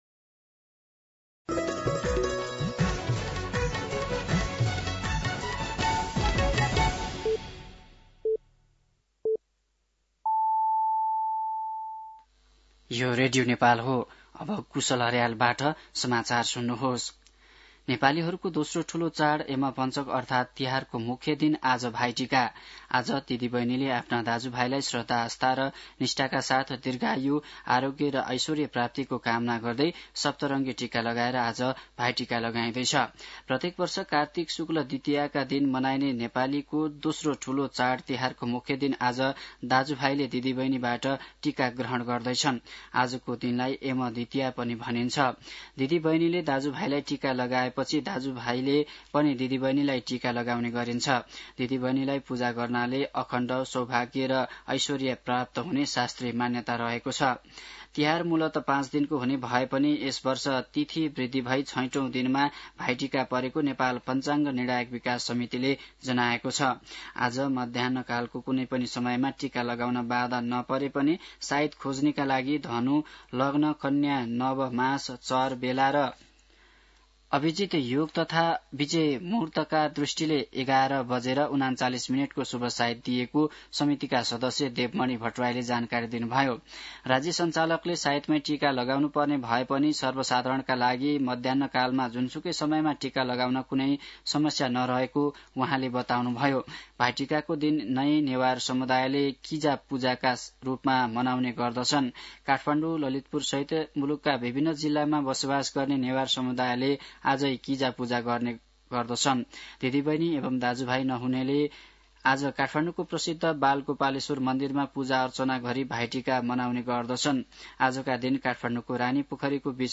An online outlet of Nepal's national radio broadcaster
मध्यान्ह १२ बजेको नेपाली समाचार : १८ पुष , २०२६
12pm-nepali-news.mp3